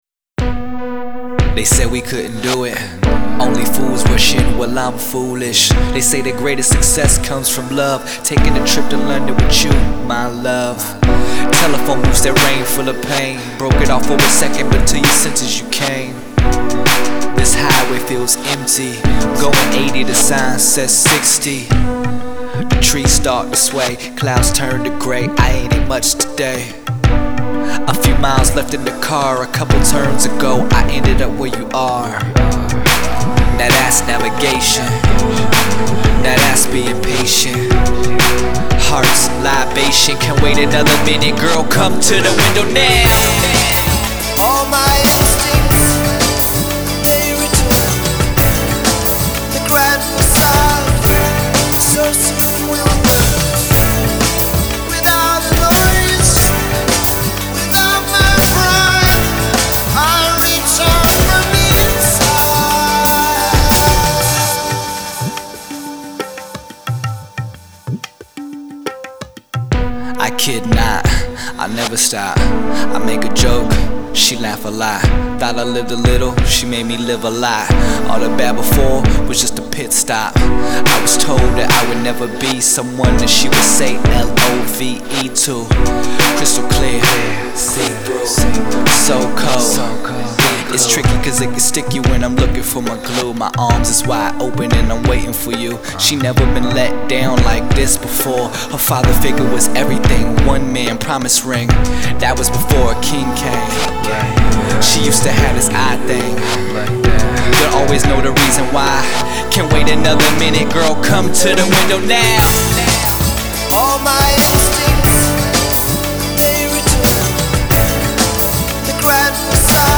lovelorn rap ballad